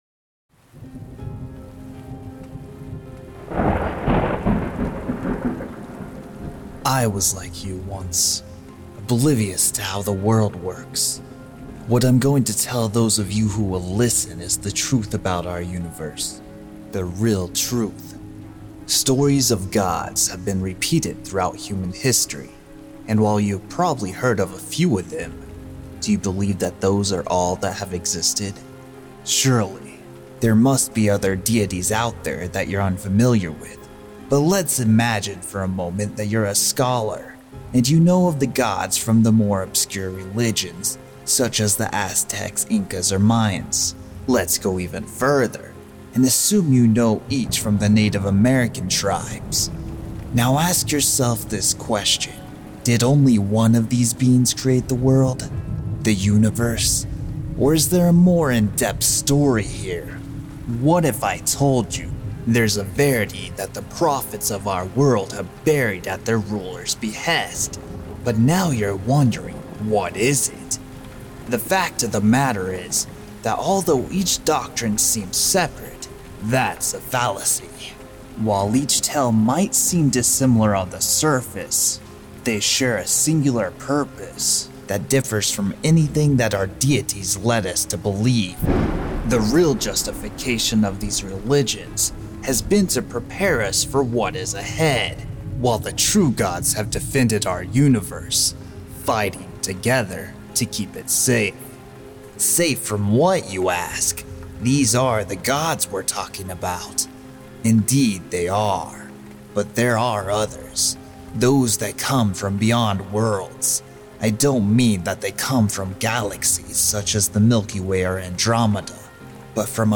Prologue for Afterworld: The Haunted Realm Beyond Our Stars. I had a lot of the actors that are in both Dawning of Darkness and Afterworld record their lines for both.